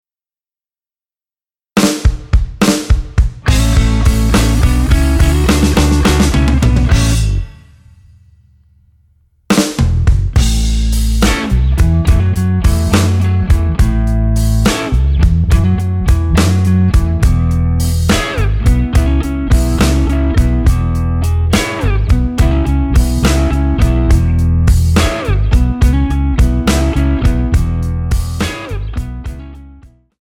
Tonart:A ohne Chor